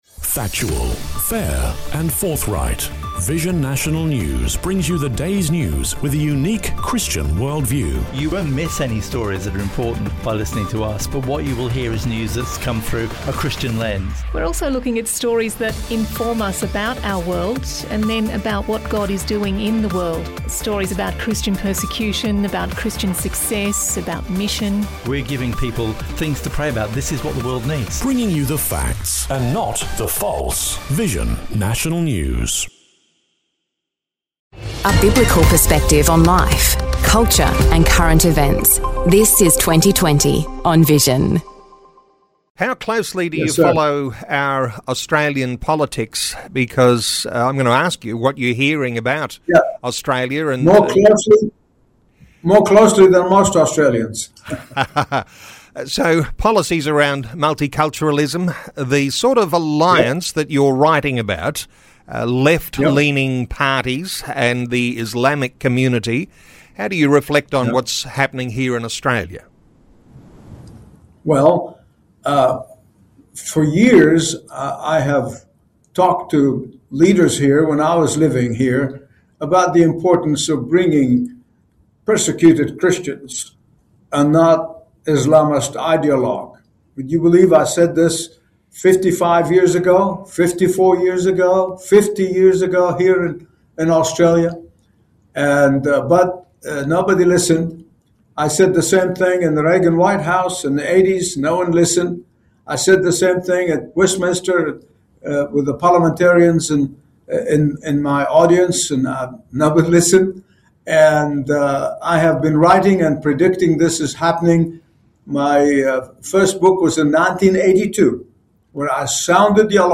An Unholy Alliance: Secularism, Islamism & Biblical Truth | Dr. Michael Youssef Interview